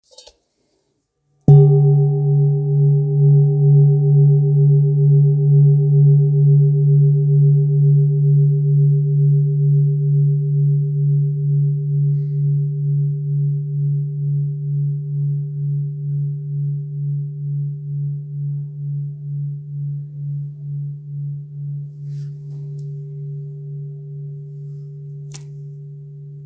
Kopre Singing Bowl, Buddhist Hand Beaten, Antique Finishing, Select Accessories, 18 by 18 cm,
Material Seven Bronze Metal
It is accessible both in high tone and low tone .
In any case, it is likewise famous for enduring sounds.